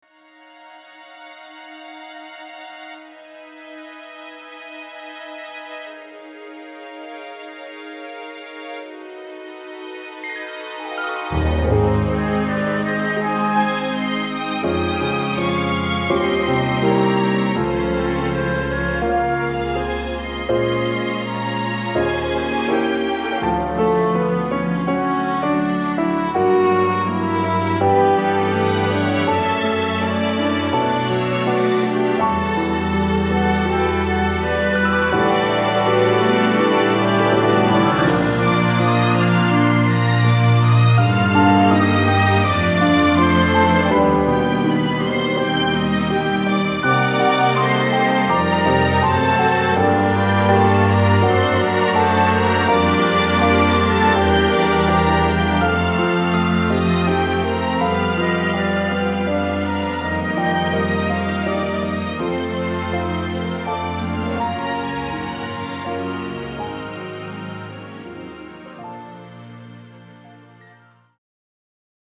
BGM试听: